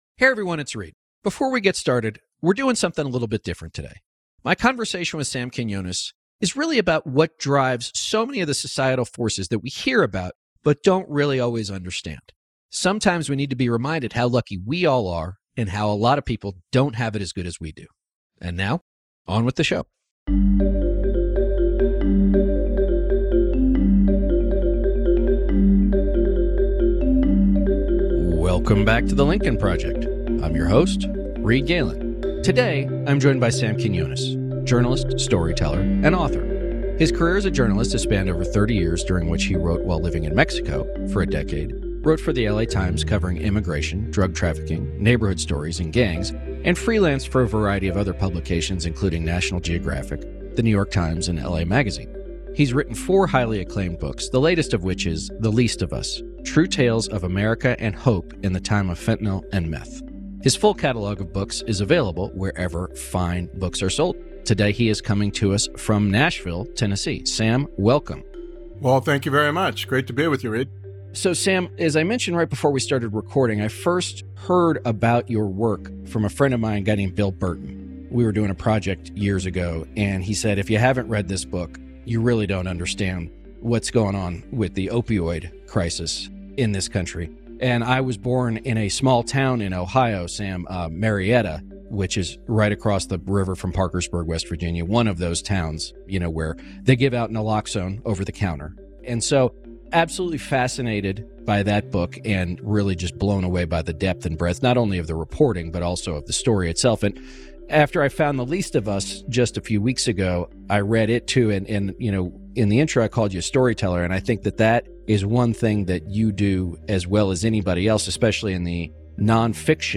two-part conversation